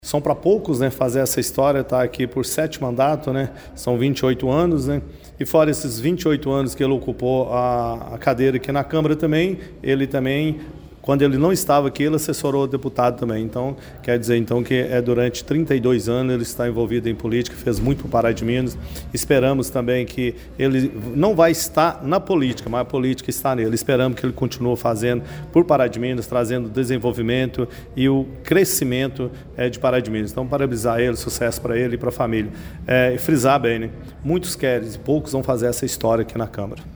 A Câmara Municipal de Pará de Minas realizou na tarde desta terça-feira, 19 de novembro, a 40ª reunião semanal ordinária no exercício de 2024.